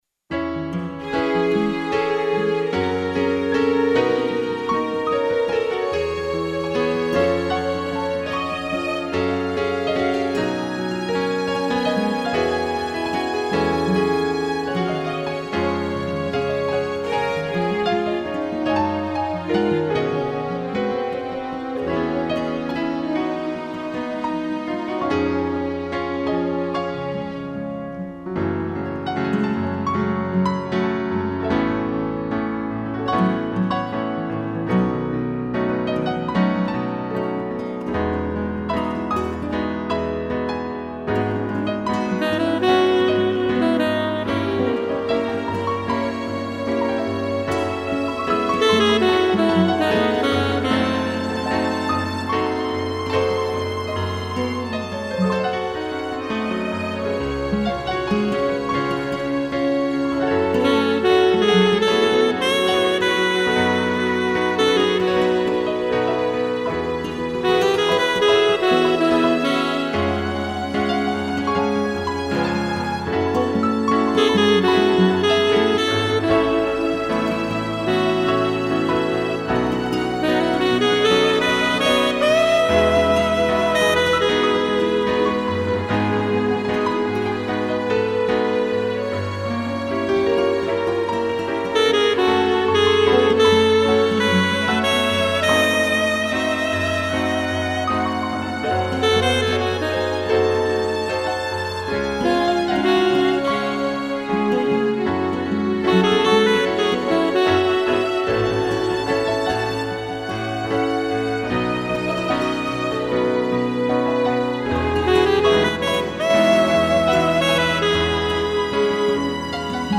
2 pianos, sax, violino
instrumental